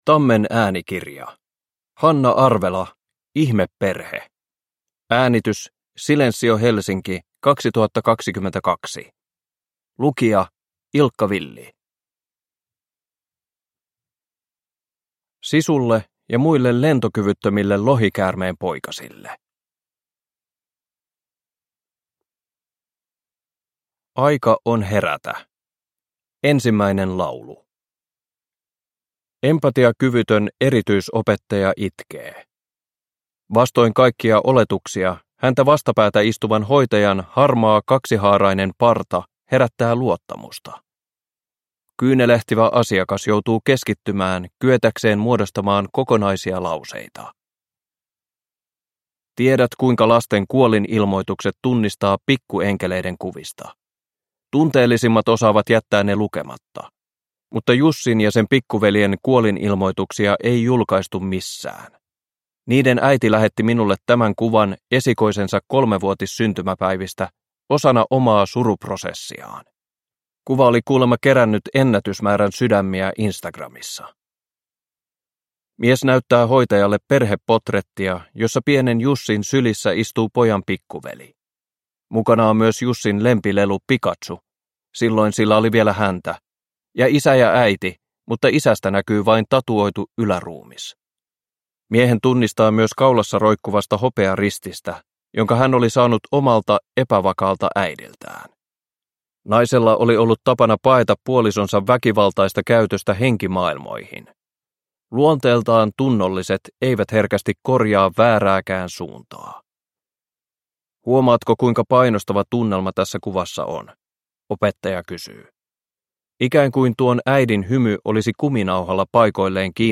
Ihmeperhe – Ljudbok – Laddas ner
Uppläsare: Ilkka Villi